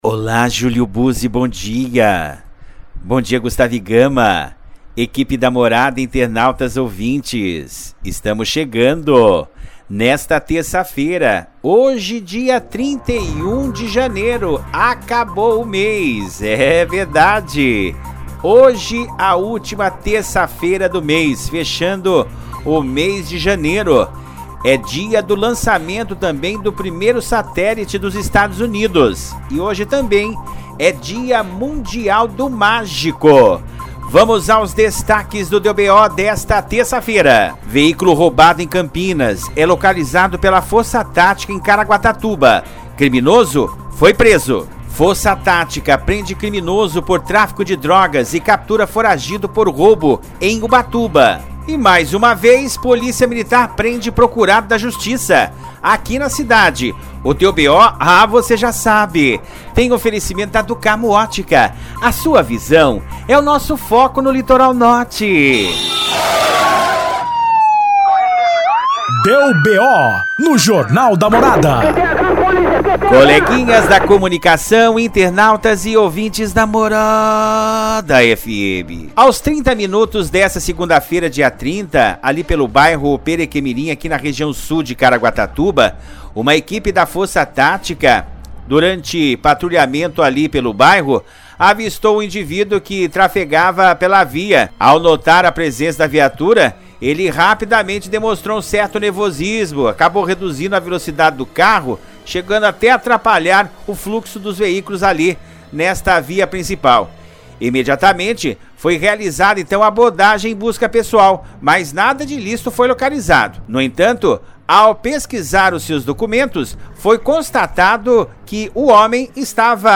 PODCAST: 31-01-23- Deu BO- segunda a sexta ao vivo na rádio Morada FM 95.5